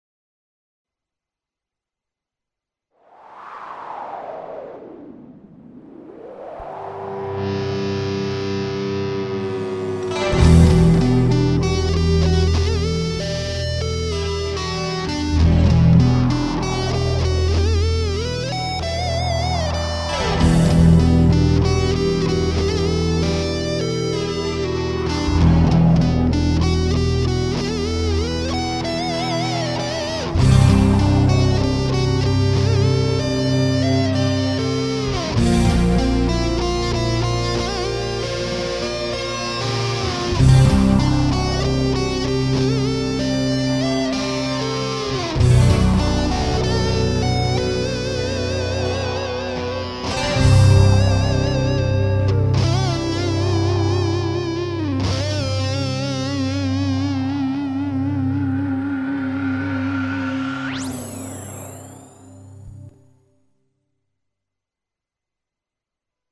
ややミステリアスな感じ〜〜！？